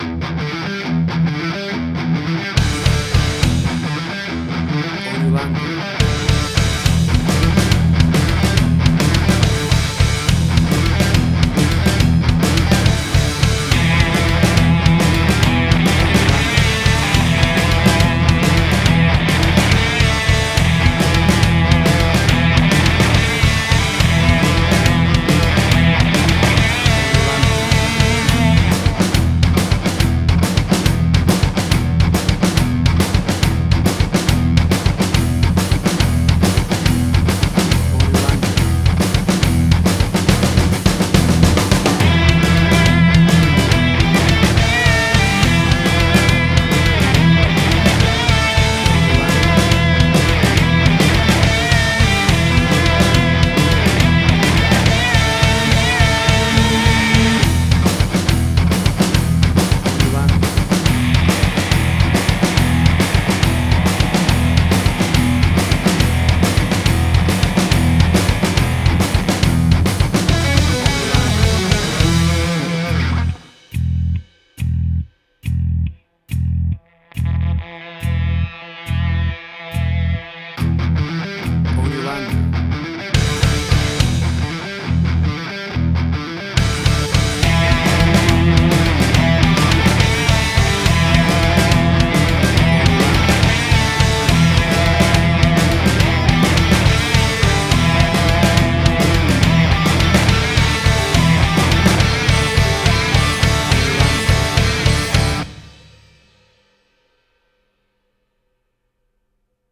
Hard Rock, Similar Black Sabbath, AC-DC, Heavy Metal.
Tempo (BPM): 139